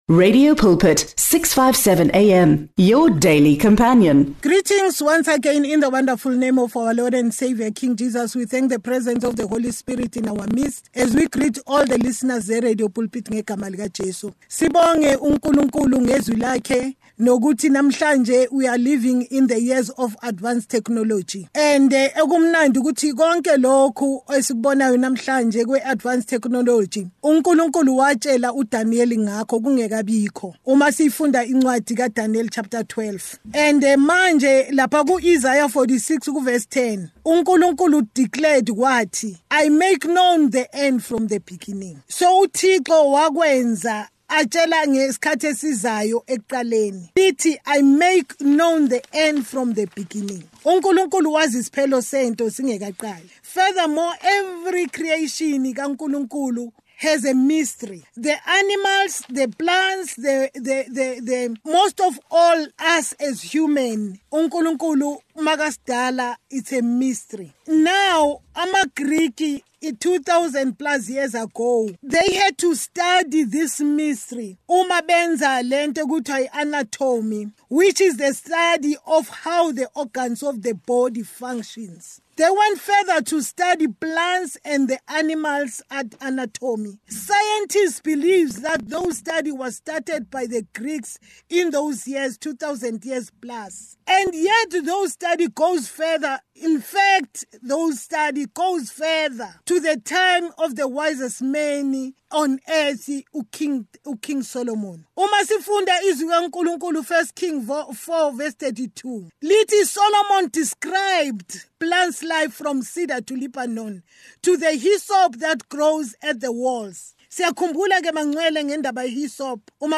A responsive prayer.
Listeners can expect a fresh Word from God early in the morning every weekday. Pastors from different denominations join us to teach the Word of God.